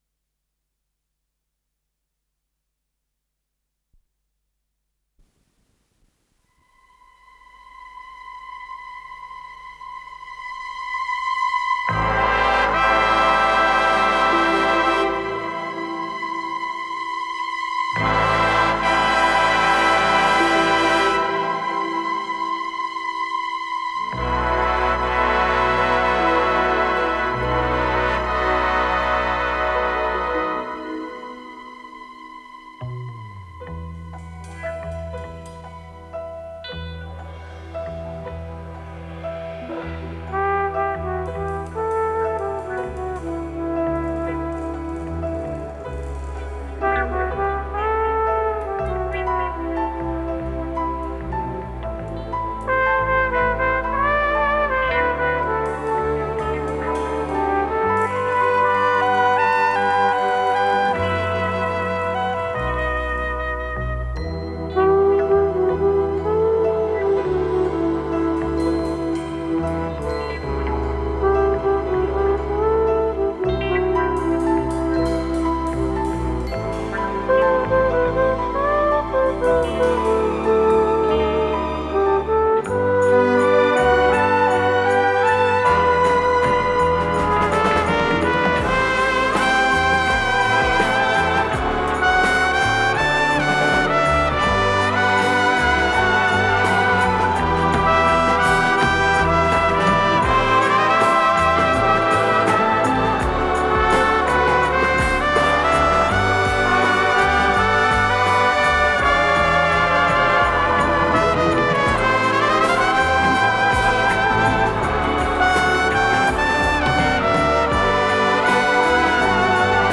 с профессиональной магнитной ленты
труба